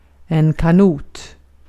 Ääntäminen
Ääntäminen Haettu sana löytyi näillä lähdekielillä: ruotsi Käännös Ääninäyte Substantiivit 1. canoe US Artikkeli: en .